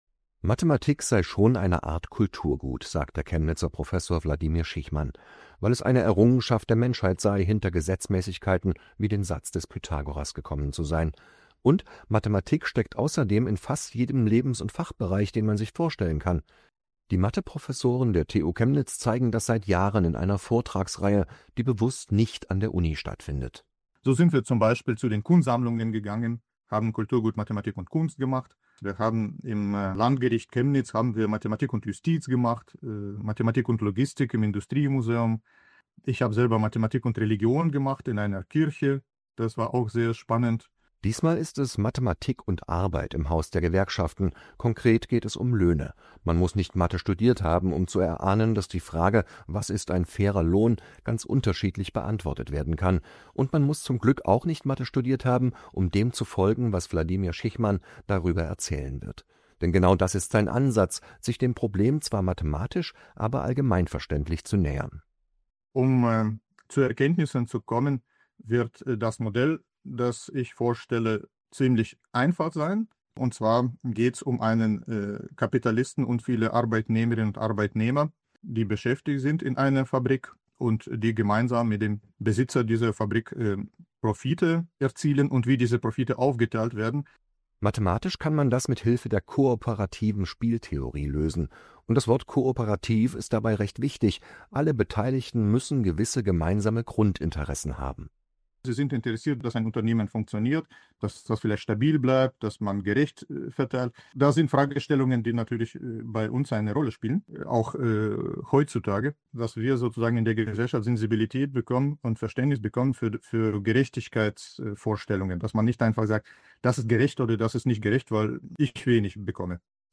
Ort: Haus der Gewerkschaften, Augustusburger Straße 31-33, 09111 Chemnitz